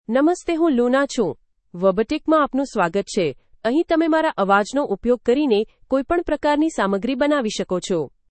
Luna — Female Gujarati (India) AI Voice | TTS, Voice Cloning & Video | Verbatik AI
Luna is a female AI voice for Gujarati (India).
Voice sample
Listen to Luna's female Gujarati voice.
Luna delivers clear pronunciation with authentic India Gujarati intonation, making your content sound professionally produced.